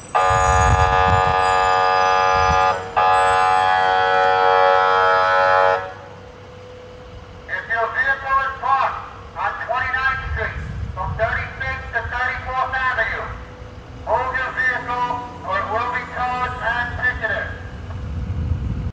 I and many others (possibly hundreds of people) woke at 7am on Monday to this unbelievable sound:
The man is blasting a foghorn and announcing that all vehicles on the street must be moved lest they be towed and ticketed.
And, vehicle-owner or not, everyone in the vicinity got to enjoy this obnoxious early-morning sound blast.